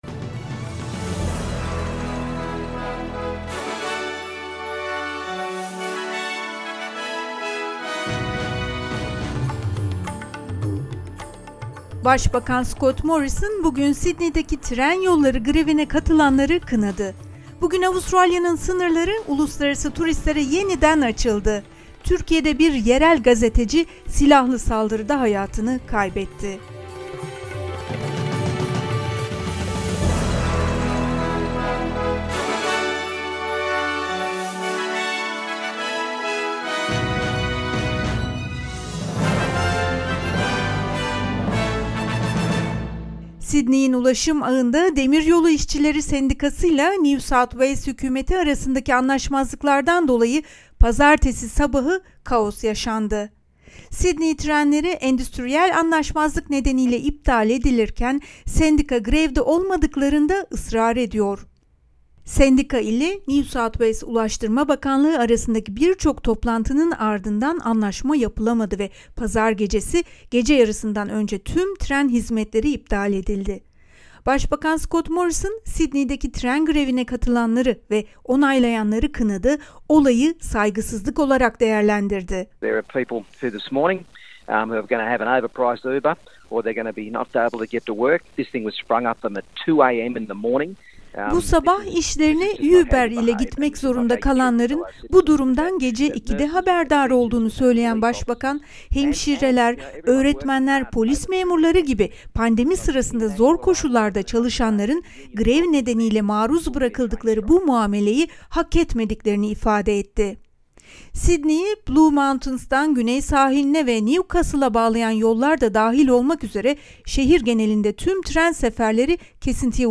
SBS Türkçe Haber Bülteni 21 Şubat